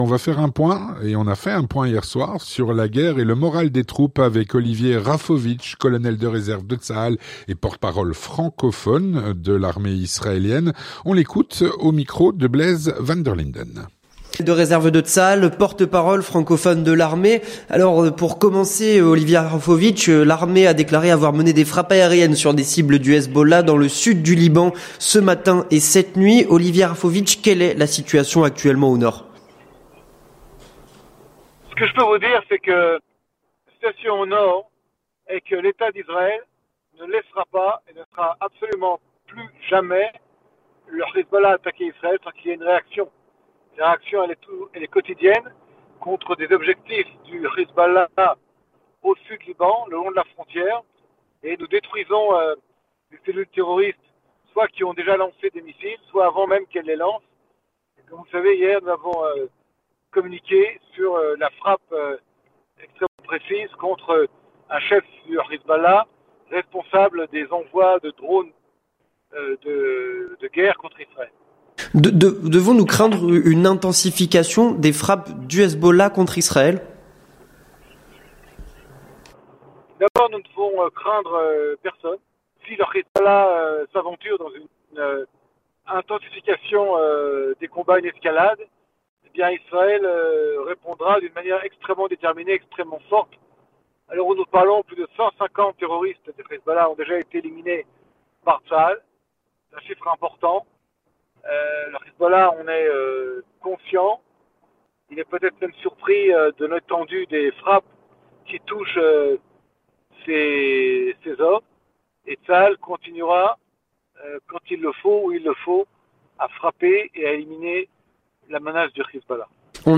L'entretien du 18H - Le point sur la guerre et le moral des troupes.